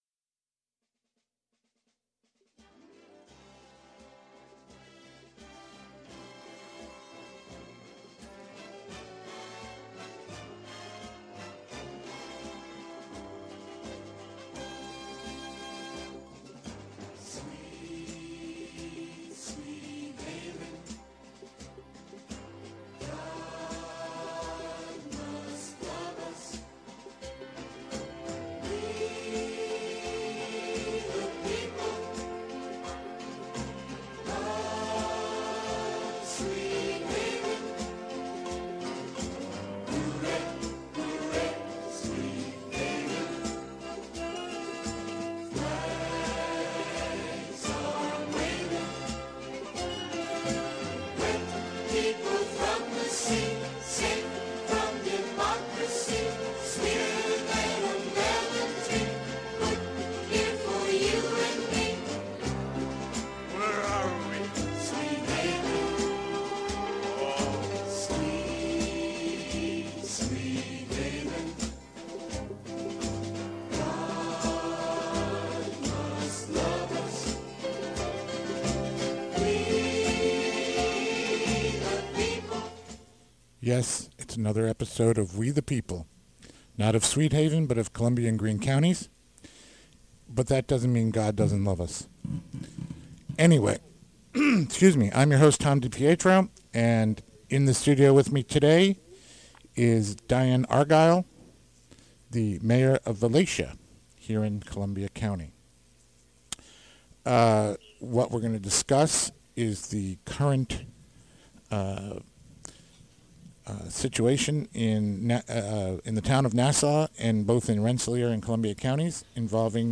A discussion about the Dewey Loeffel Superfund site in Nassau, the EPA's decision to discharge treated toxins into the Valatie Kill and the agency's failure to notify local municipalities about its plans.